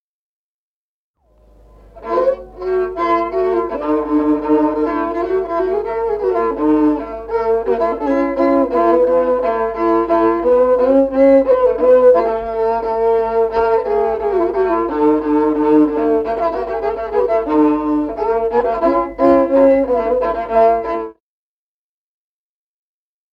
Музыкальный фольклор села Мишковка «Хмелю», партия 2-й скрипки.